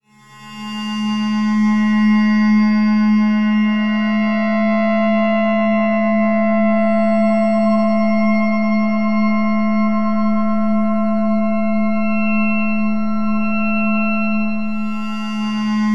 F# FX.wav